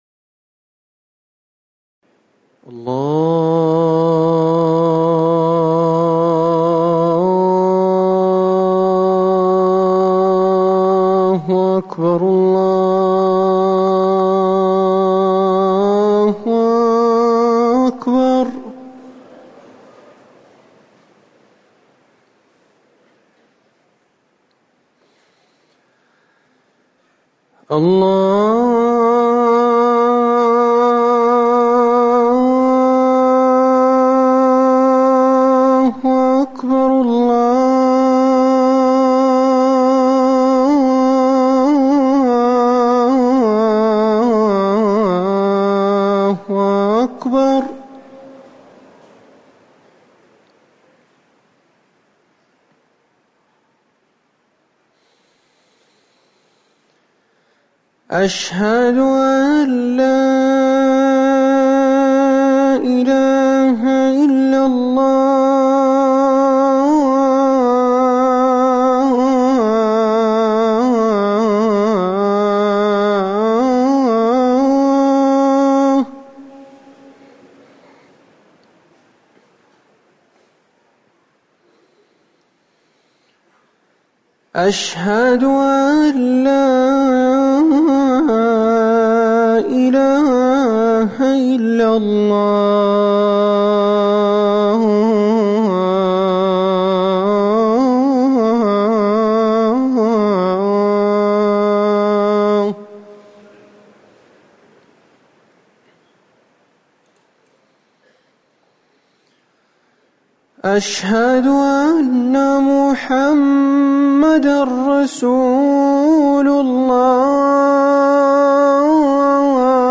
أذان الفجر الأول - الموقع الرسمي لرئاسة الشؤون الدينية بالمسجد النبوي والمسجد الحرام
تاريخ النشر ٢٨ صفر ١٤٤١ هـ المكان: المسجد النبوي الشيخ